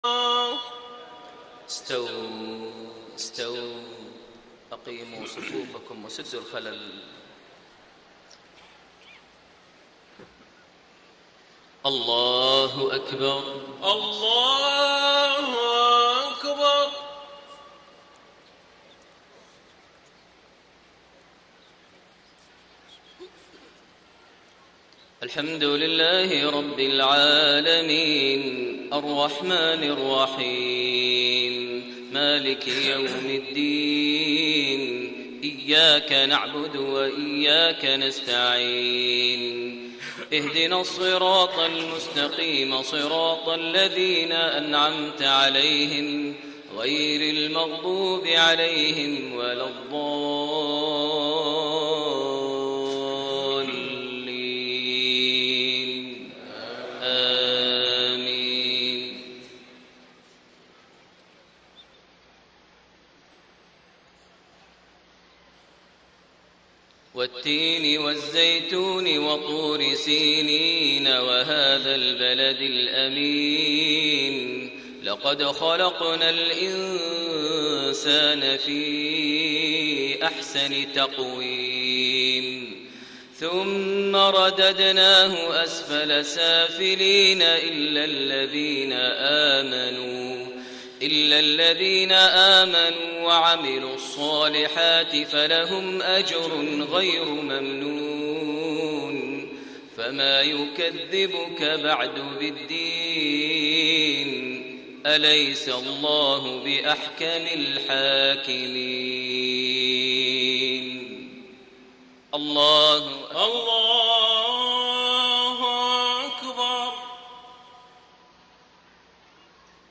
صلاة المغرب 4 شوال 1433هـ سورتي التين و النصر > 1433 هـ > الفروض - تلاوات ماهر المعيقلي